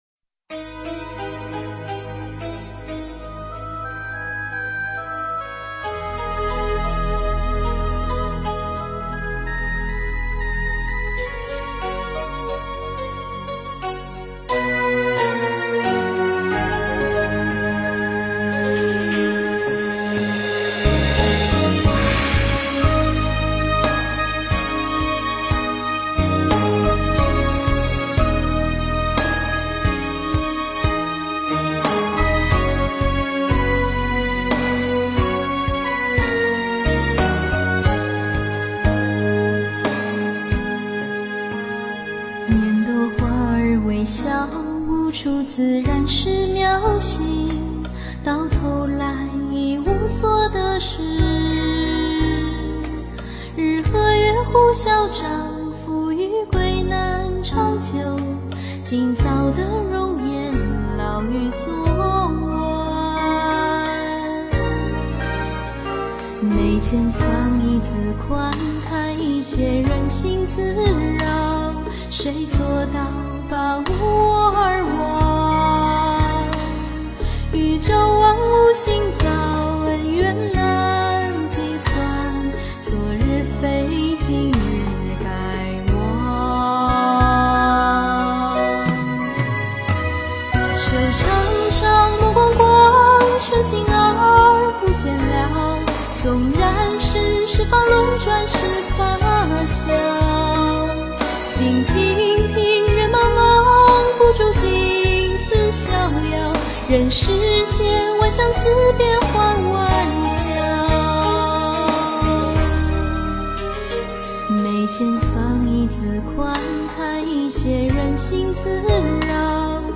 物我二忘 诵经 物我二忘--佛教音乐 点我： 标签: 佛音 诵经 佛教音乐 返回列表 上一篇： 无边 下一篇： 喜欢佛的圣号 相关文章 观音菩萨偈--唱经给你听 观音菩萨偈--唱经给你听...